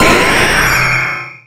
Cri de Méga-Roucarnage dans Pokémon Rubis Oméga et Saphir Alpha.
Cri_0018_Méga_ROSA.ogg